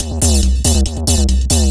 DS 140-BPM B3.wav